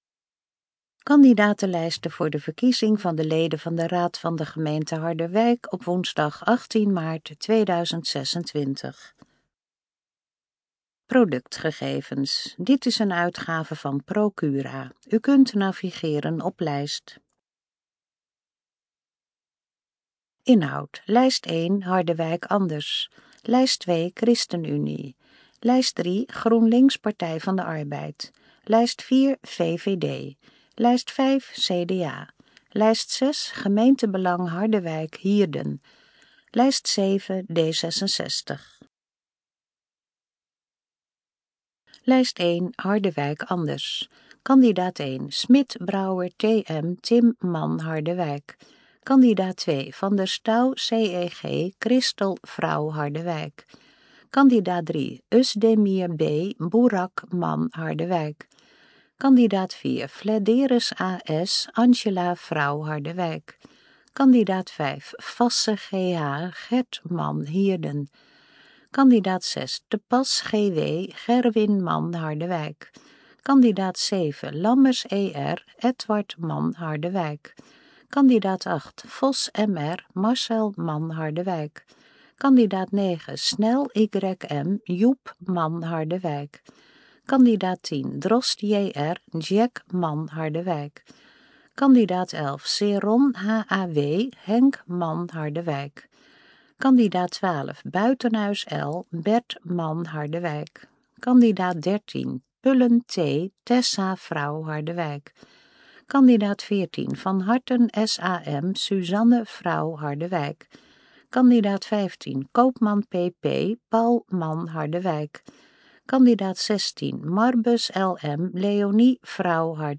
Kandidatenlijst_Gesproken_Vorm_GR26_Harderwijk.mp3